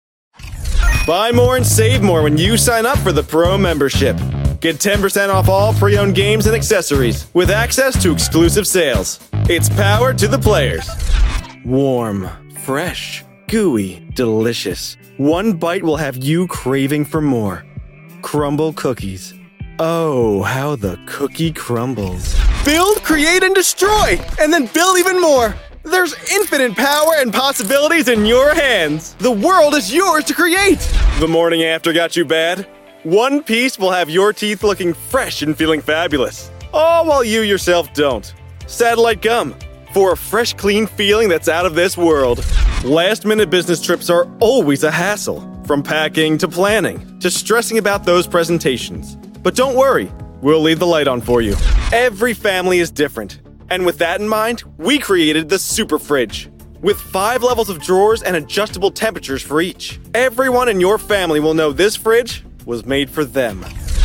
广告【活力】